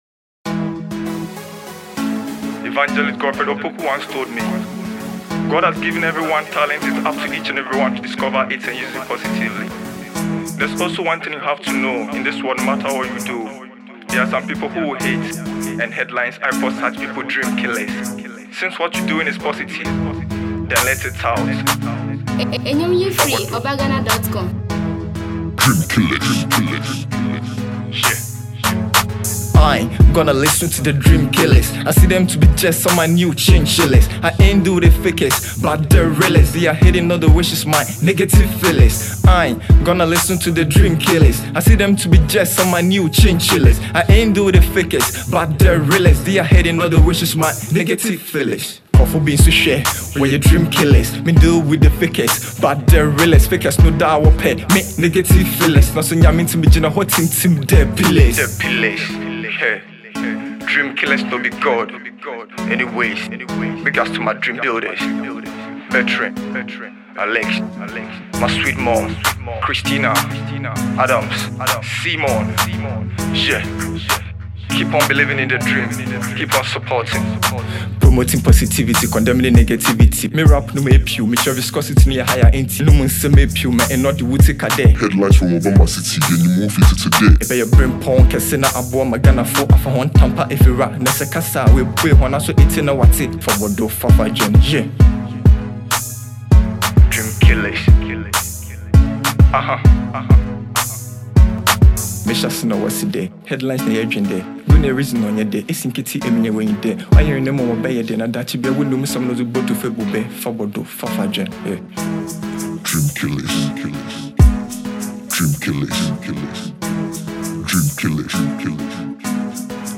Ghana Music
Cape Coast fante rapper